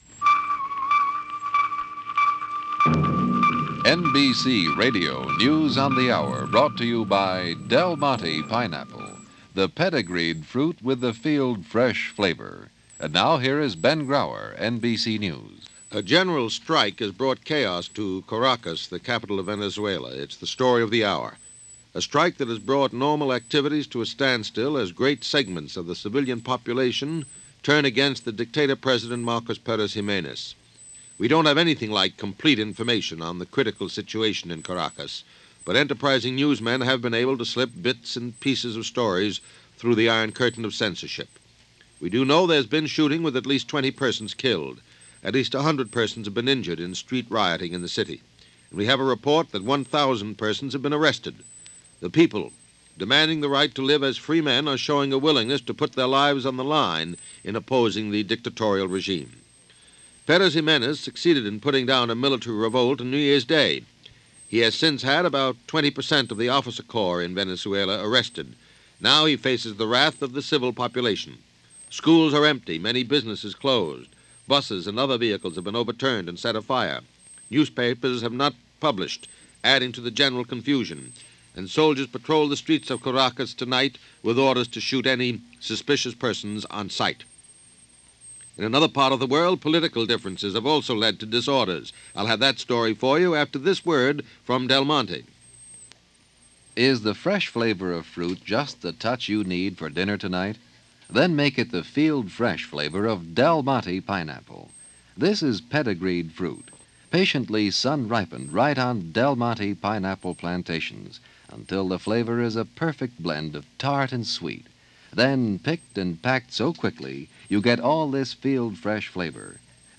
And with the ongoing news from Caracas, that’s just a sample of what was going on, this rather violent day, January 21, 1958 as reported by NBC News On The Hour.